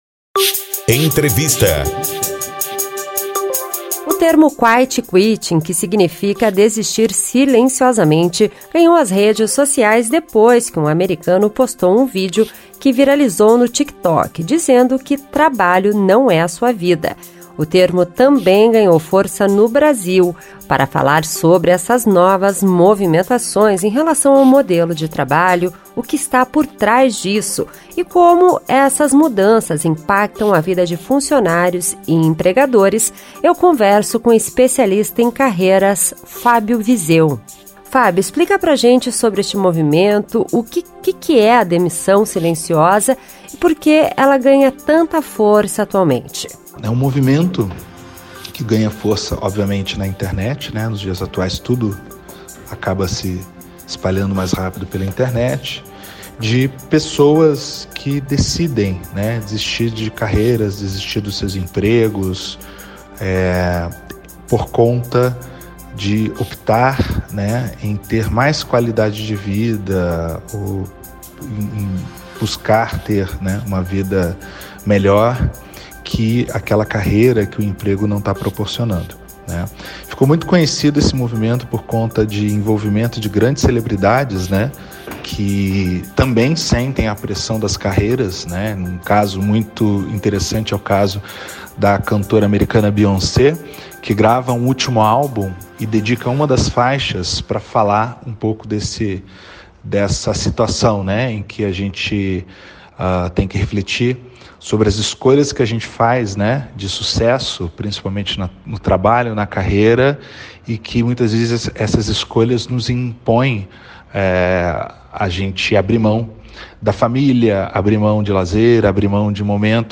conversa com o especialista em carreiras